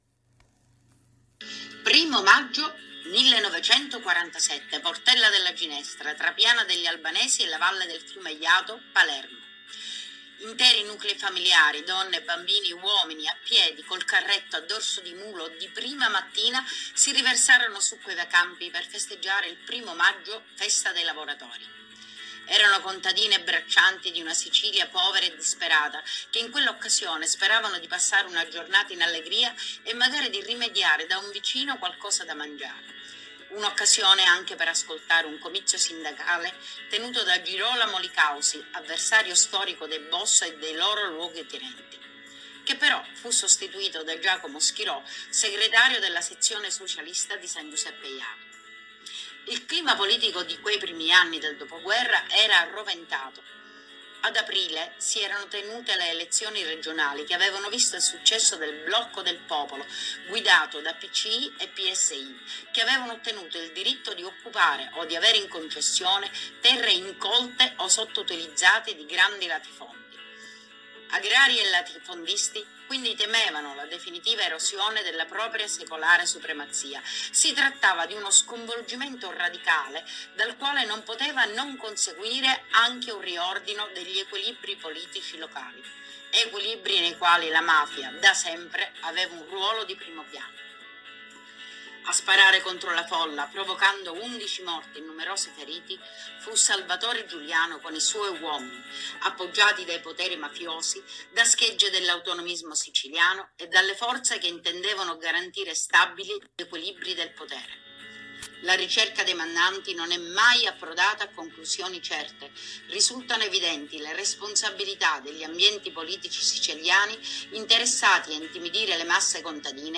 ballata